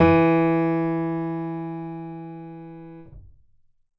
keyboard.wav